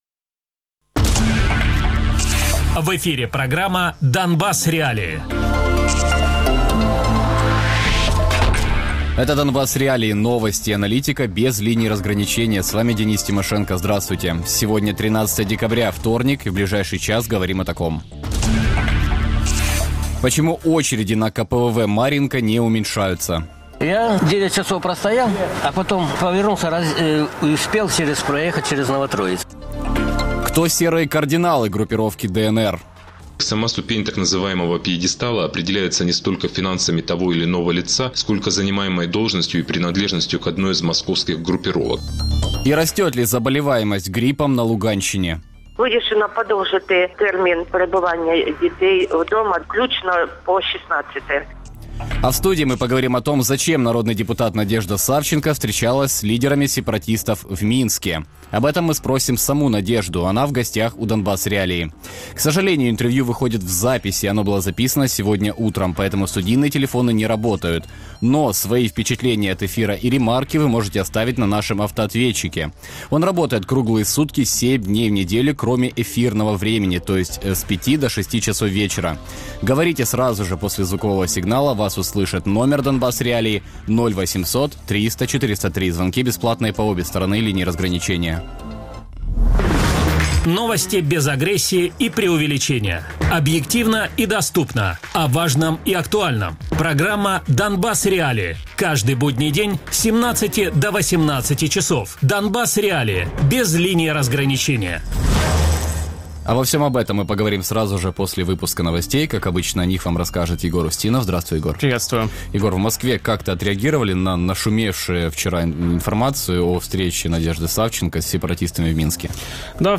Гость: Народный депутат Надежда Савченко Радіопрограма «Донбас.Реалії» - у будні з 17:00 до 18:00.